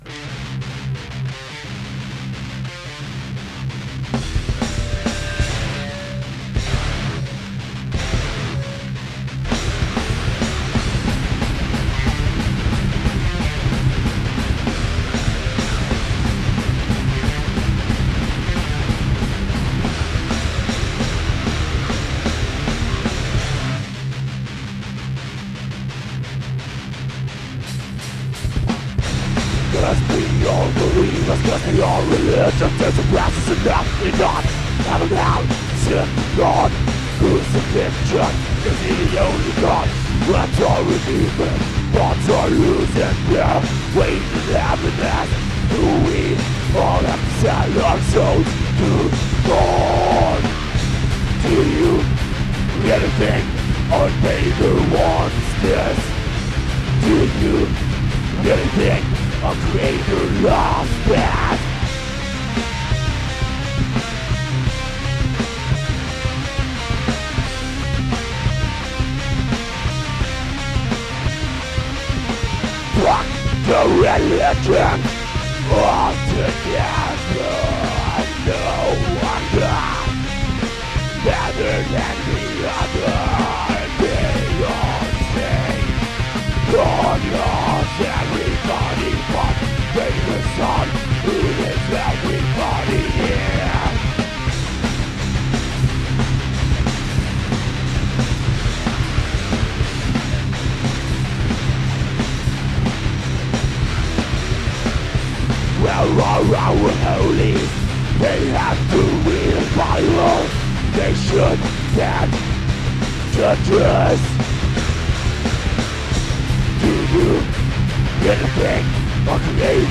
Gitarre
Gesang/Geschrei
Bass/Gesang
Drums
Demo Songs